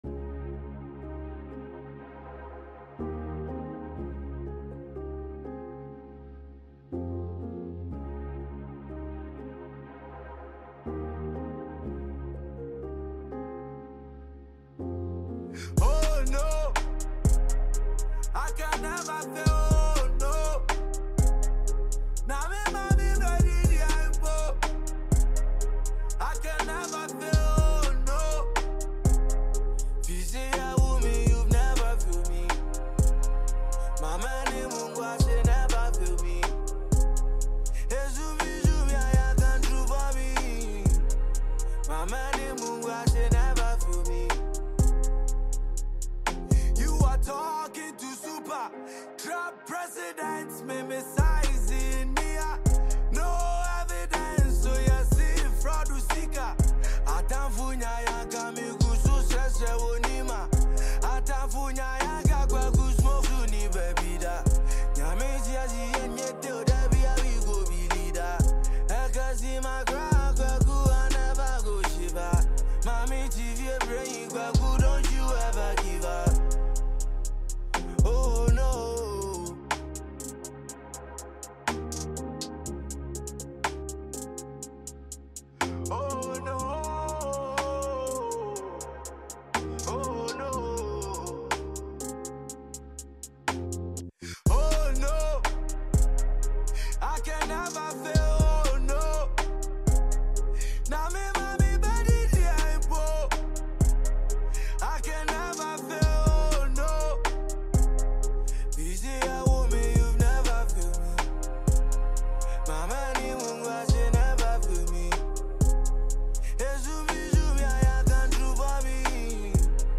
Ghanaian award-winning rapper